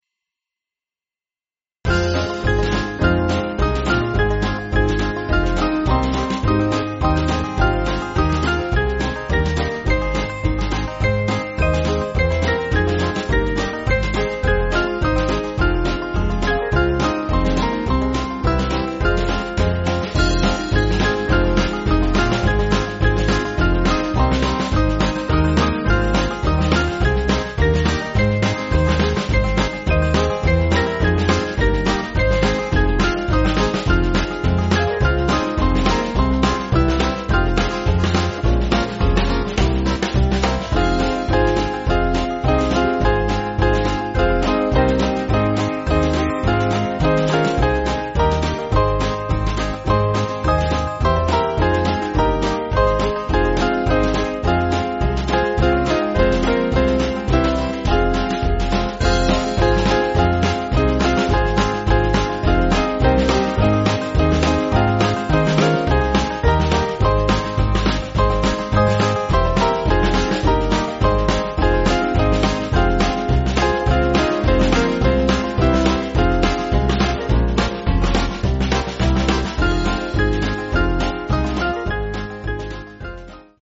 Small Band
4/Fm